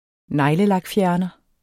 Udtale [ ˈnɑjləlɑgˌfjæɐ̯nʌ ]